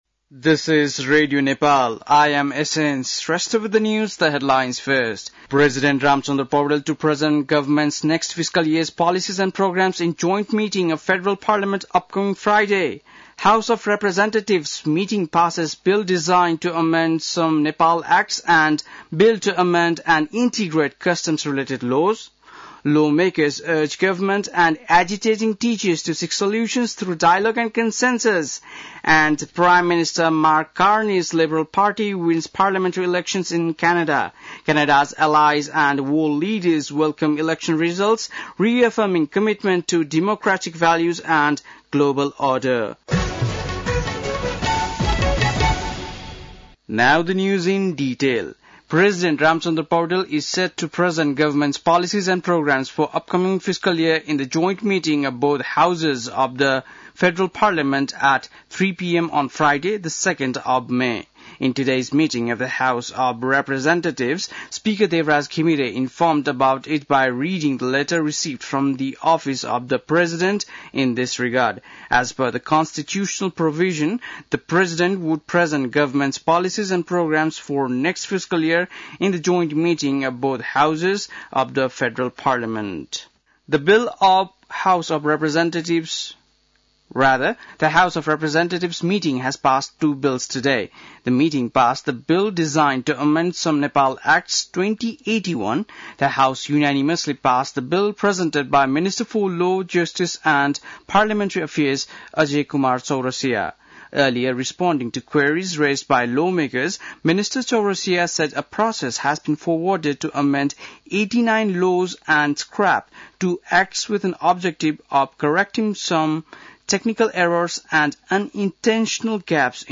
बेलुकी ८ बजेको अङ्ग्रेजी समाचार : १६ वैशाख , २०८२